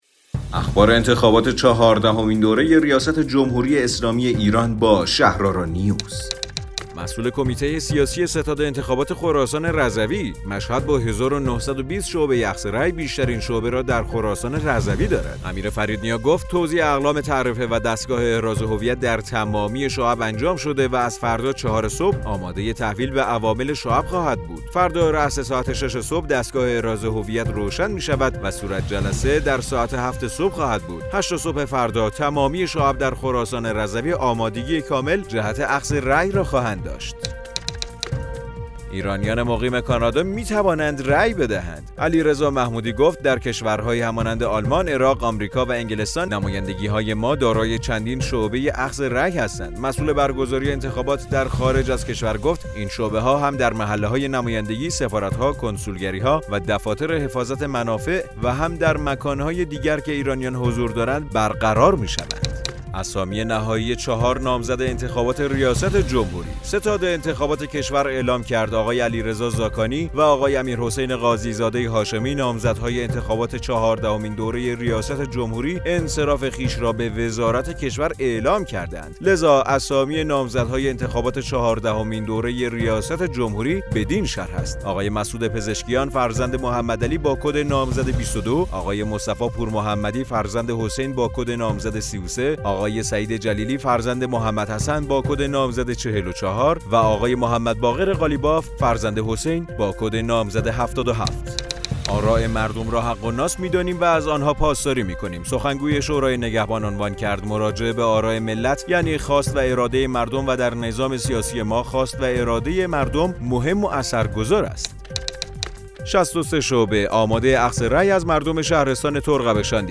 رادیو شهرآرا، پادکست خبری انتخابات ریاست جمهوری ۱۴۰۳ است.